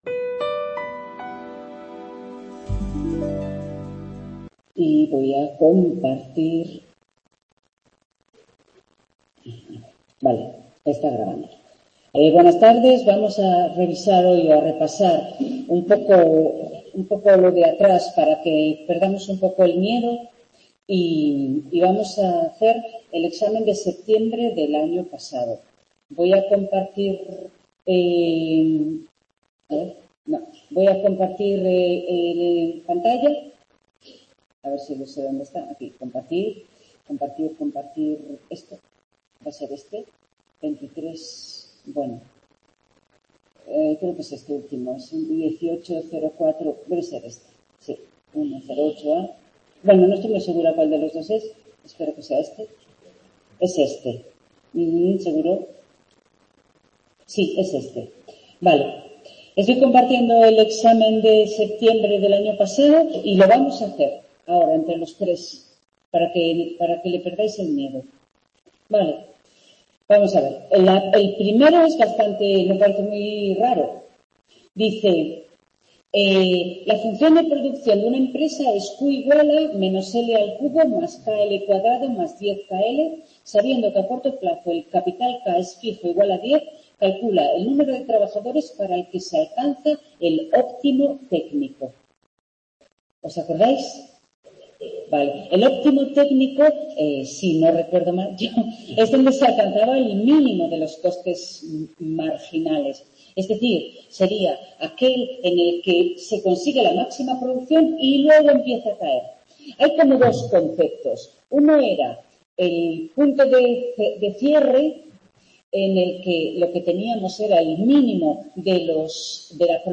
En esta tutoría se resolvieron ejercicios de exámenes y las dudas que tenían los alumnos en el aula